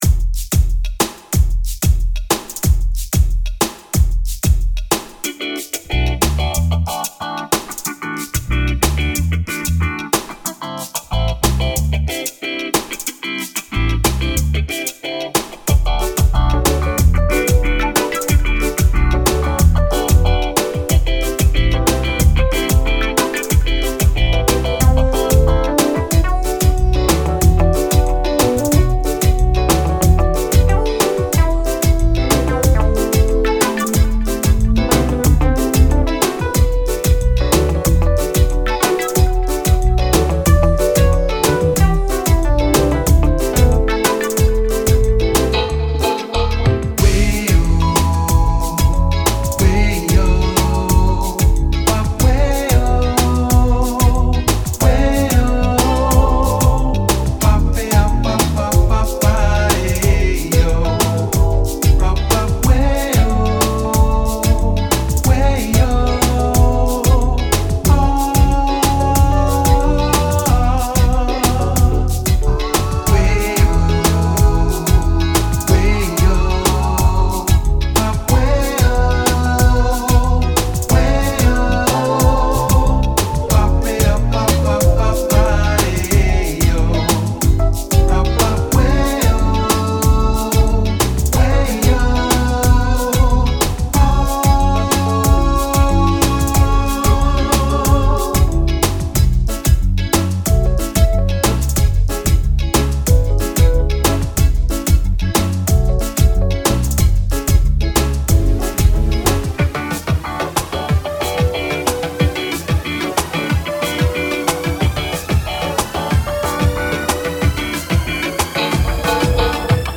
まさにBalearic Reggaeな逸品です！